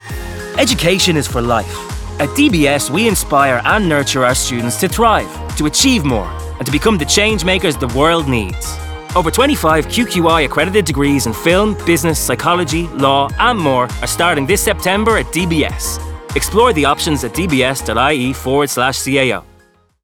DBS-Radio-1-20-sec-CAO-Parents.wav